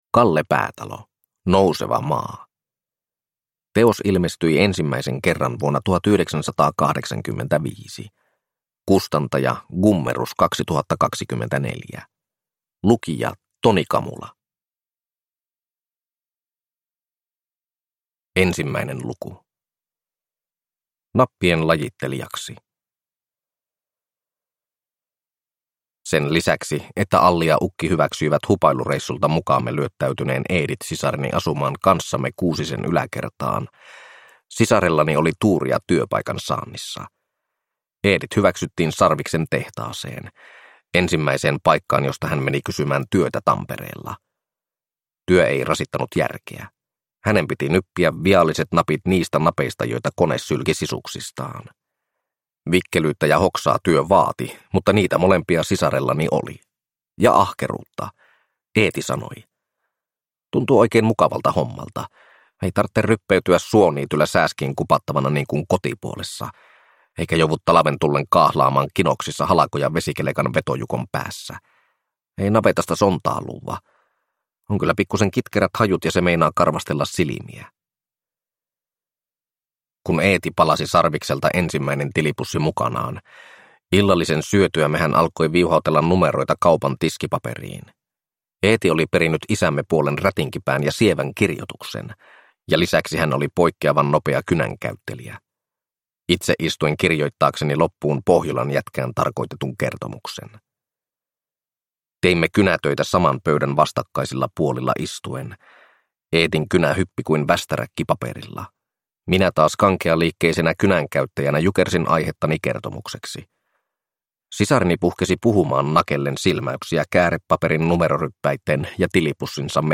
Nouseva maa (ljudbok) av Kalle Päätalo